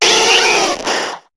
Cri de Goupix dans Pokémon HOME.